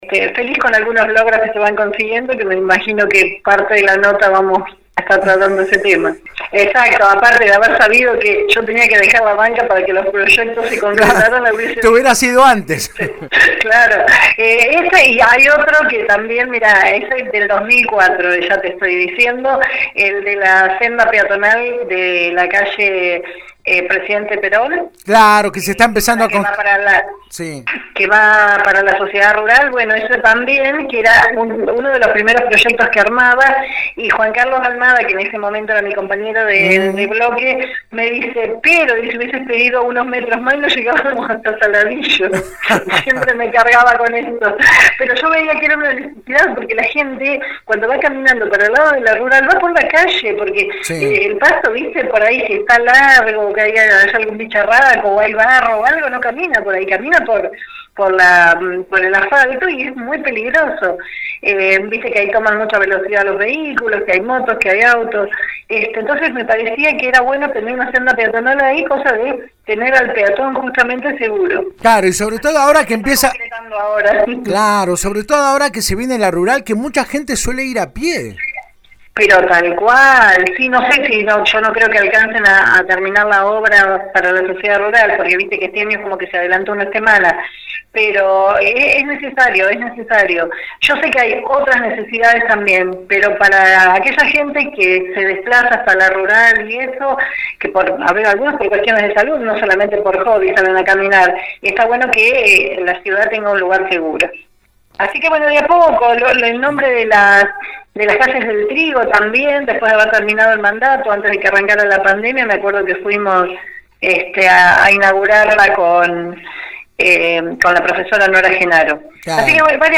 La ex concejal -mandato cumplido- habló este martes por la 91.5 luego de la presentación y colocación del Desfibrilador Externo Automático el pasado domingo en el Parque Plaza Montero.